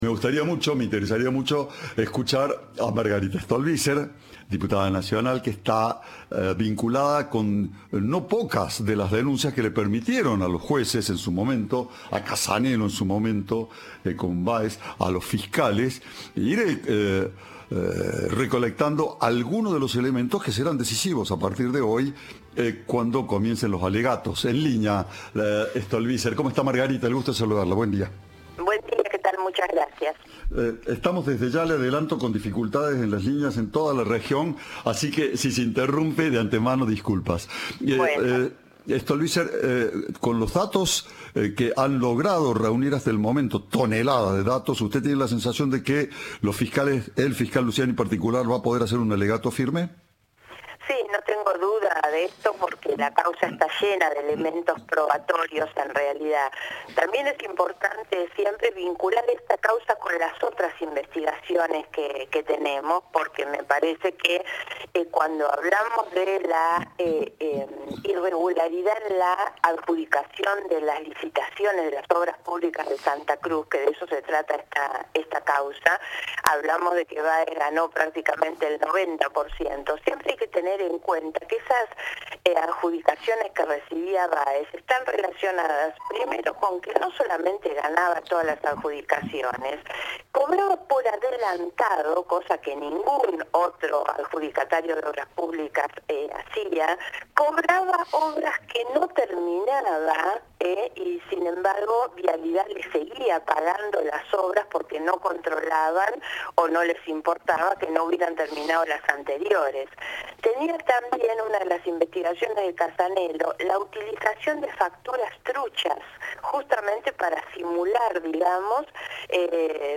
La diputada nacional habló con Cadena 3 sobre el juicio por supuestos delitos con la obra pública en Santa Cruz entre 2003 y 2015, contra la vicepresidenta, Lázaro Báez y 11 imputados.